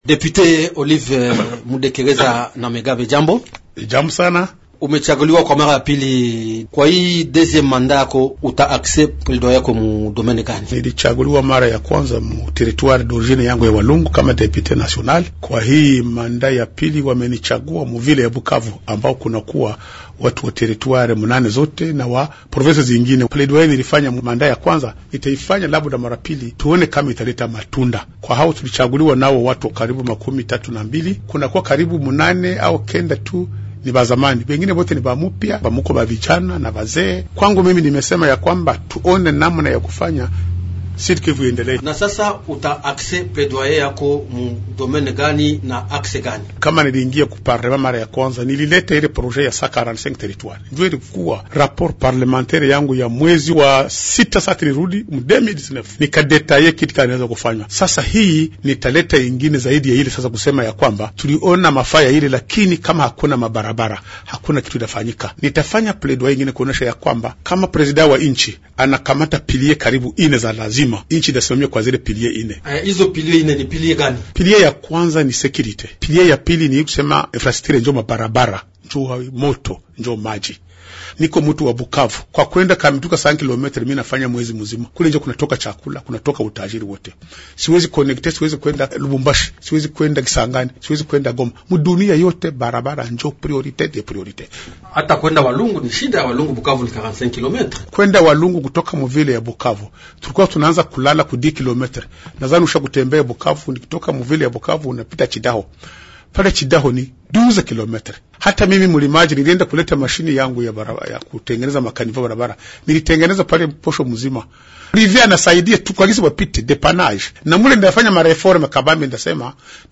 Mwalikwa wetu wa siku ni mbunge wa taifa Olive Mudekereza, aliye tajwa kwa mda na ceni mchaguliwa muji wa Bukavu, katika jimbo la Kivu ya kusini.